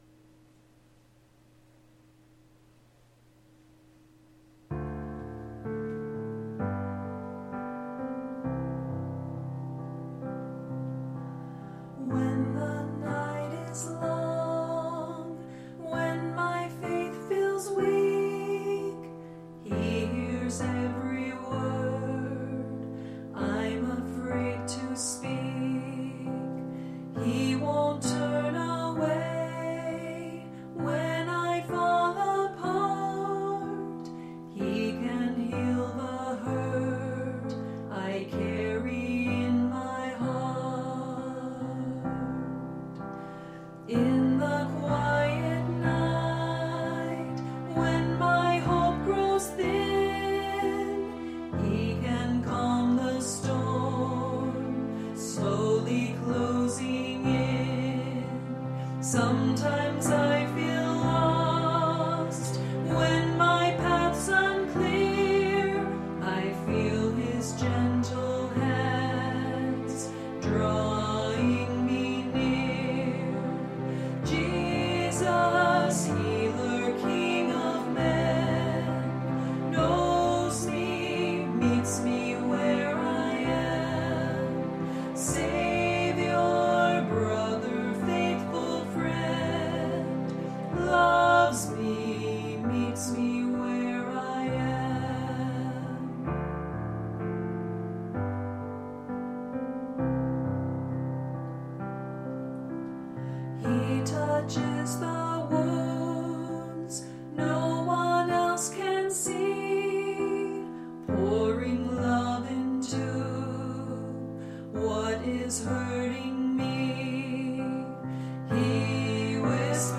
Voicing/Instrumentation: SA , Duet
Medium Voice/Low Voice